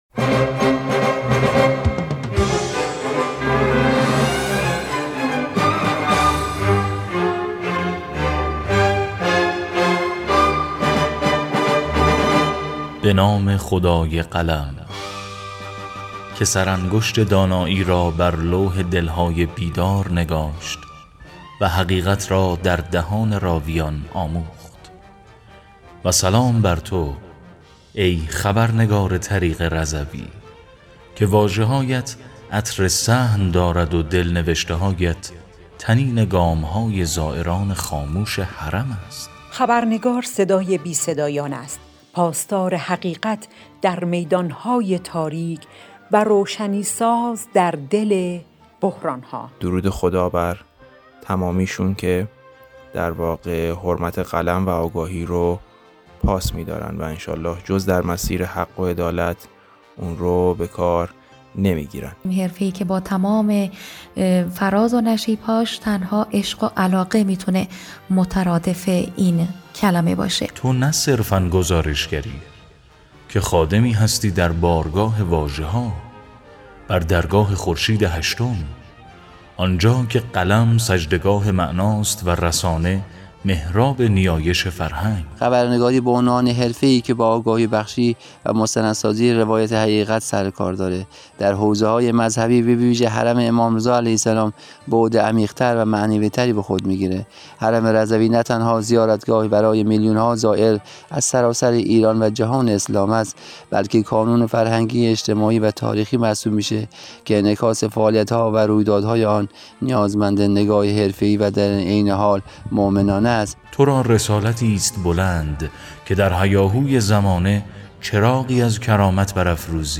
در پادکستی ویژه و شنیدنی، خبرنگاران آستان نیوز و رادیو رضوی با کلامی آکنده از عشق و صداقت، روز خبرنگار را گرامی داشتند.